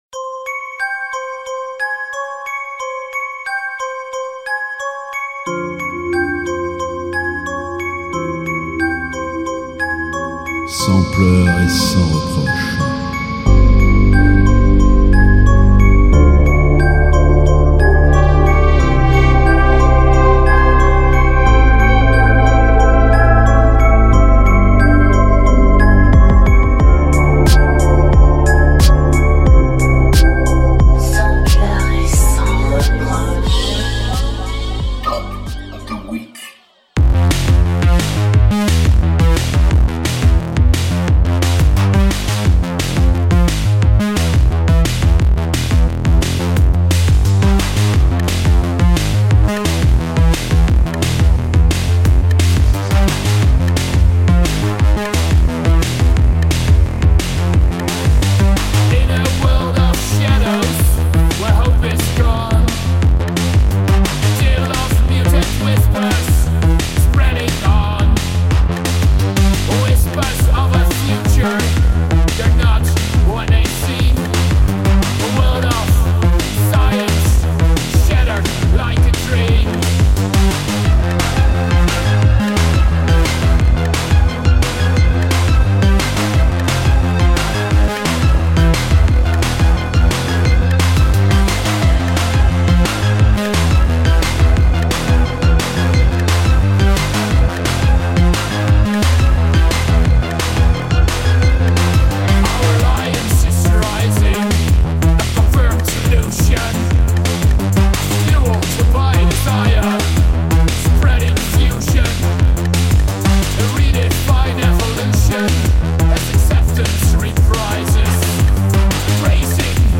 (Radio broadcast)
EBM - DARKTECHNO - INDUSTRIEL & RELATED MUSIC